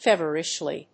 音節fé・ver・ish・ly 発音記号・読み方
/ˈfɛvɝˌɪʃli(米国英語), ˈfevɜ:ˌɪʃli:(英国英語)/